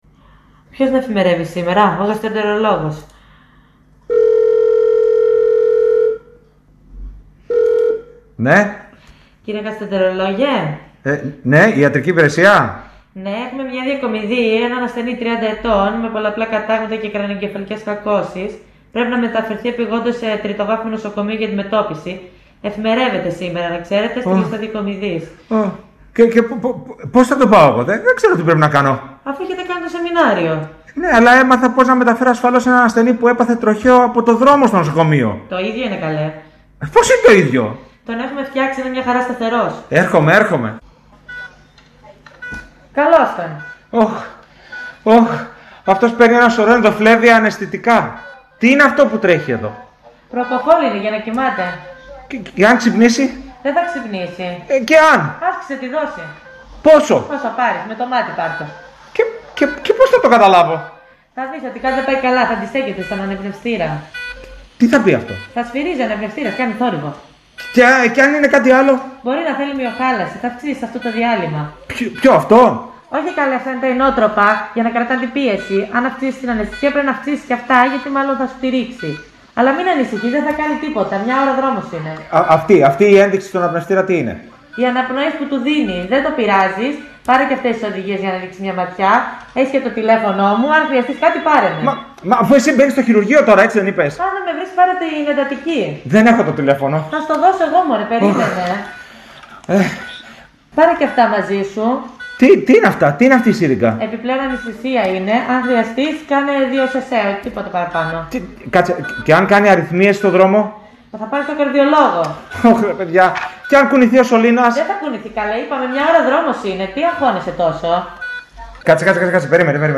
Ακούστε το… χιουμοριστικό ηχητικό που έφτιαξαν οι γιατροί του Νοσοκομείου Ρεθύμνου.